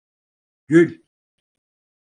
Pronunciato come (IPA) [ɟyl]